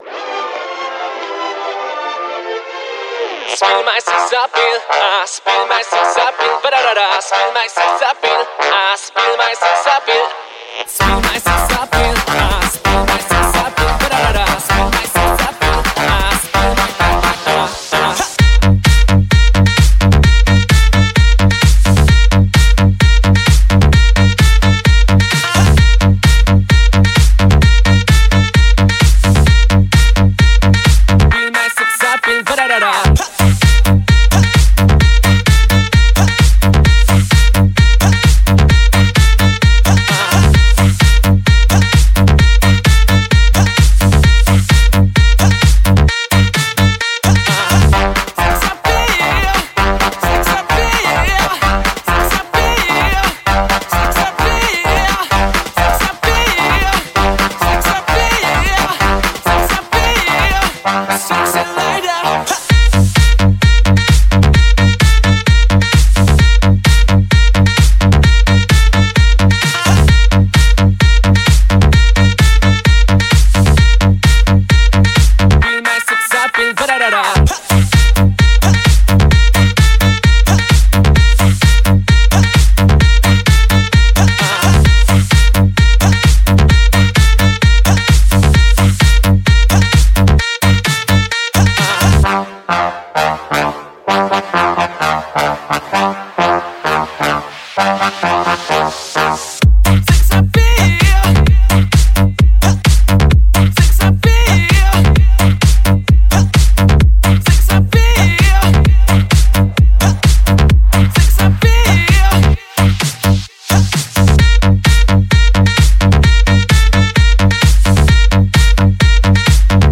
скачать клубную музыку